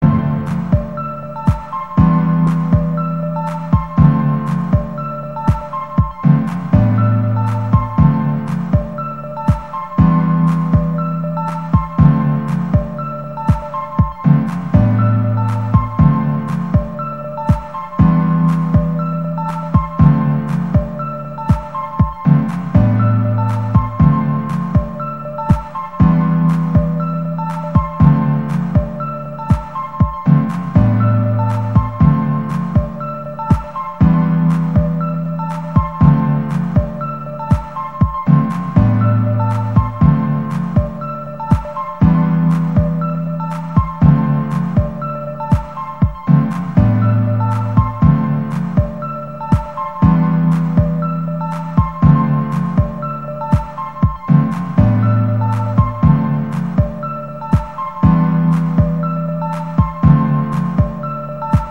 ピアノのパターンが気に入ったので、これをもとに作ってみました。
明るく終わる映画のエンディングっぽい感じ。